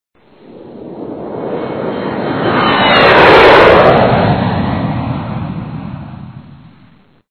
民(mín)航客機(jī)和(hé)
民航客机.mp3